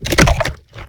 flesh1.ogg